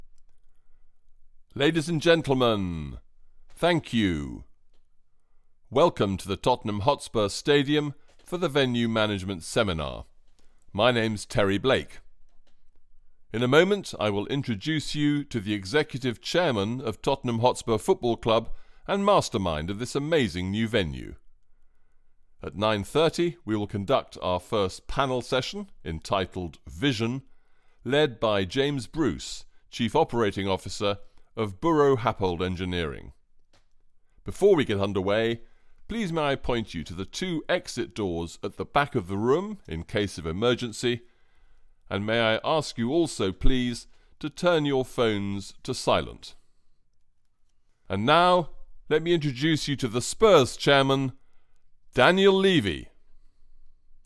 MASTER OF CEREMONIES
EMCEE-Stadium-Seminar.mp3